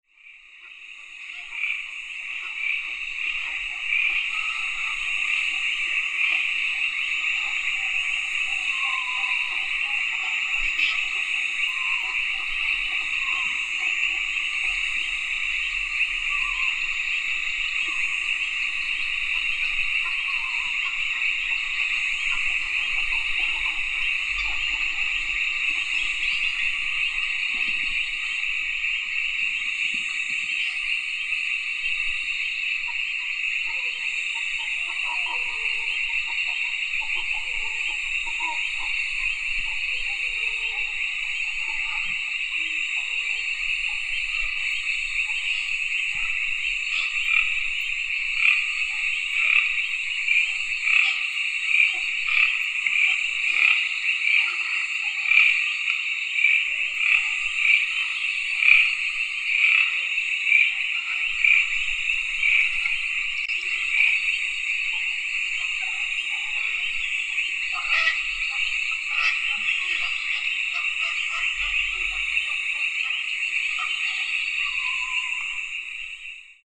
This material was recorded in different tropical ecosystems of the Colombian Orinoquia (this region is a big savannah furrowed of rivers) in the sunrises, the sundown and the night during two weeks in April 2013 in the Meta department and complemented with one recording of the 2011 in the Tuparro Natural Park.
The recordings were made in natural ecosystems without acoustic pollution.
Shore of a lake in the night of the Meta departament
Birds, insects and frogs